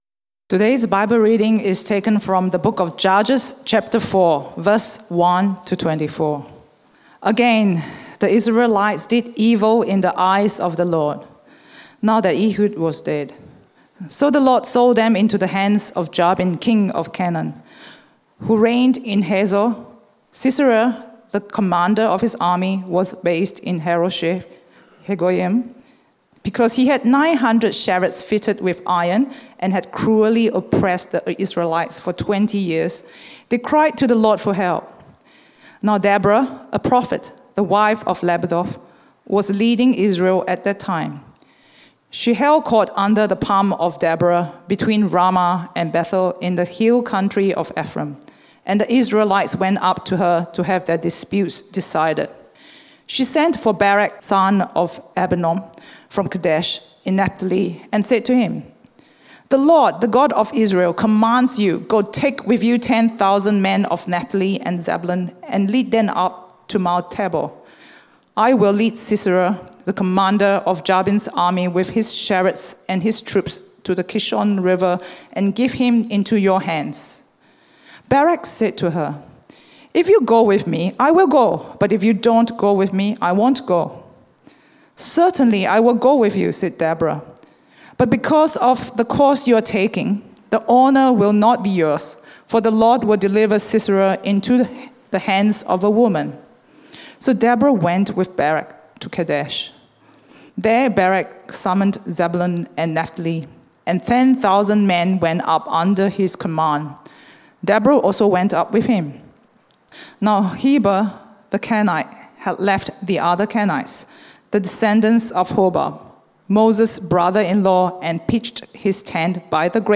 Unexpected Judges in Israel Sermon outline Share this page